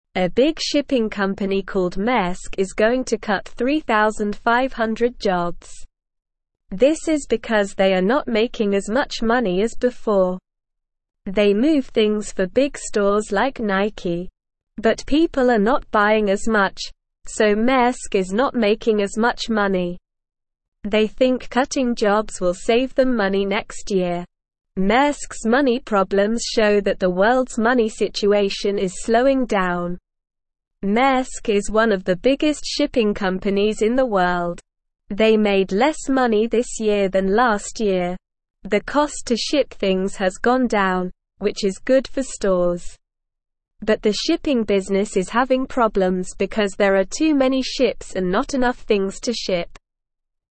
Slow
English-Newsroom-Beginner-SLOW-Reading-Big-Shipping-Company-Maersk-Cuts-Jobs-Due-to-Money-Problems.mp3